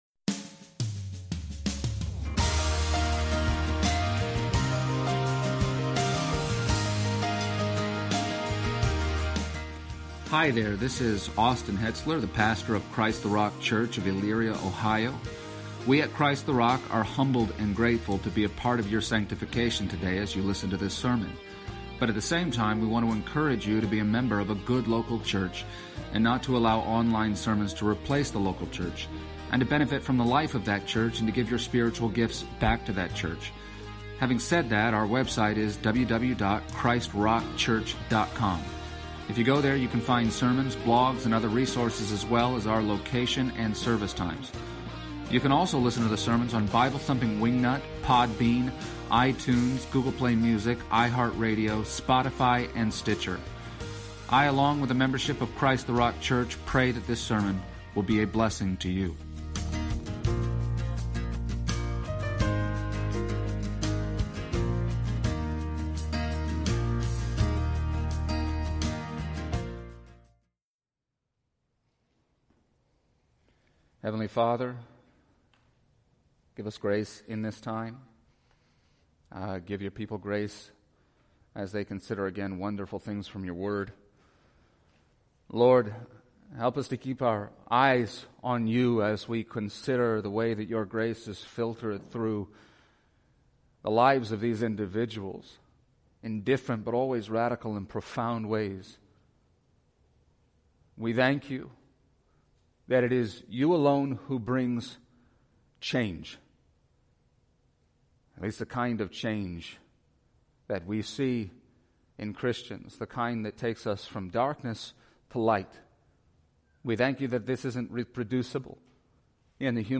Exposition of the Gospel of John Passage: John 20:19-31 Service Type: Sunday Morning %todo_render% « Why Mary Magdalene?